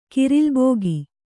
♪ kirālubōgi